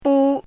怎么读
逋 [bū]